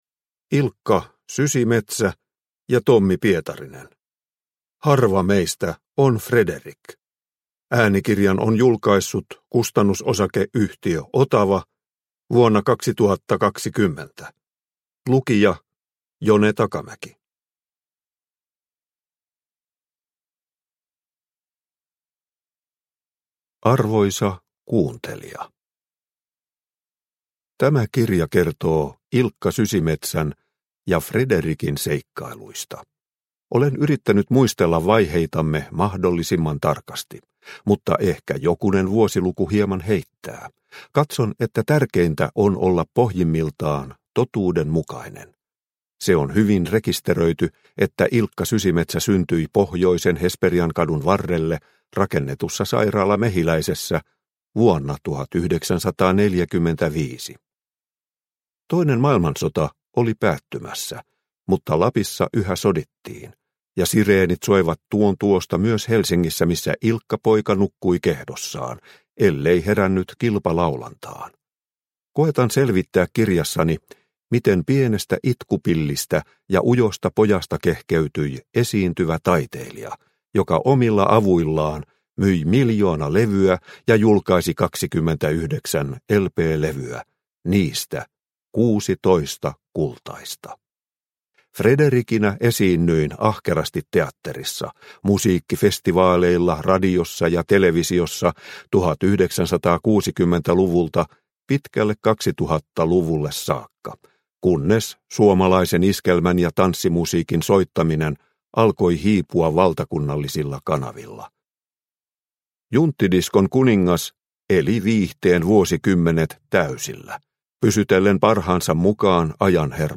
Harva meistä on Frederik – Ljudbok – Laddas ner